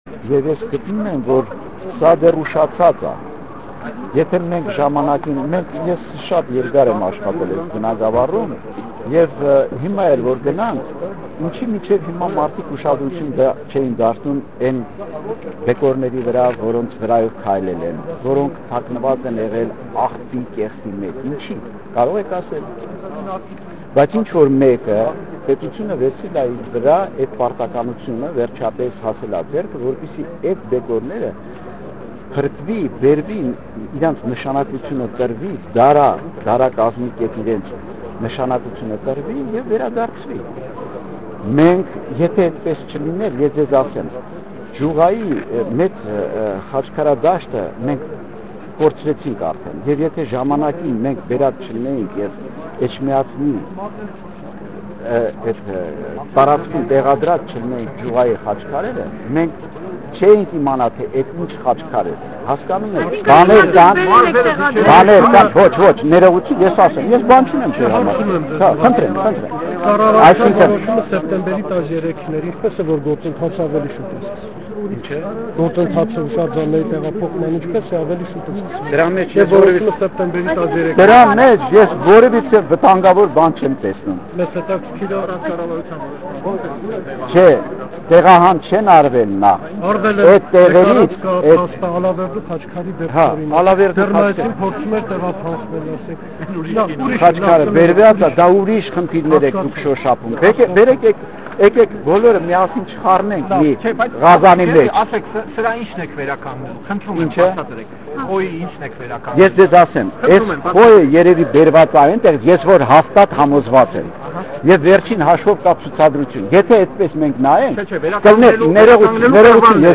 «Кто бы спас само министерство культуры» – акция протеста перед зданием правительства (Видеоматериал, аудиозапись)